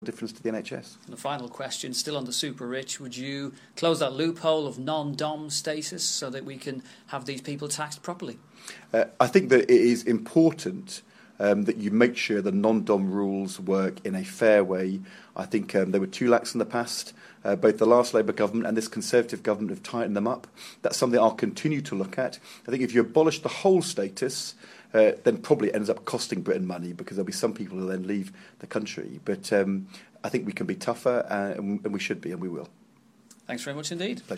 The Shadow Chancellor speaking to BBC Radio Leeds, 9 January 2015